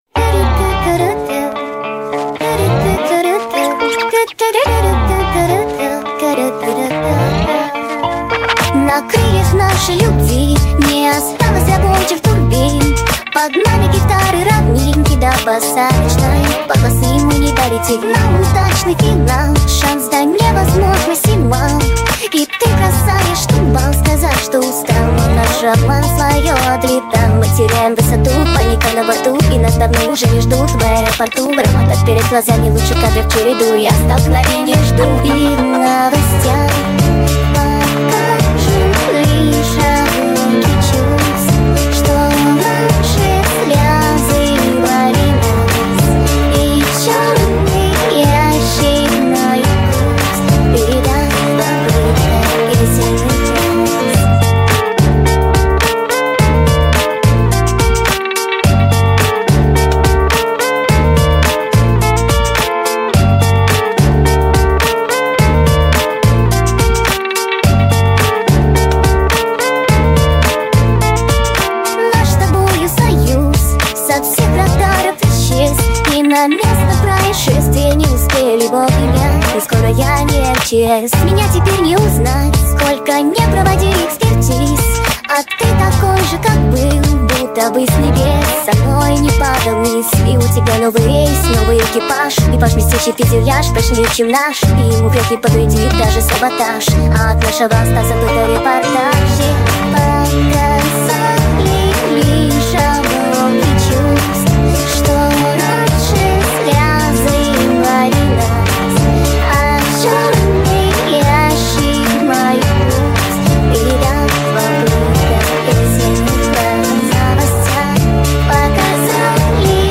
Speed Up TikTok Remix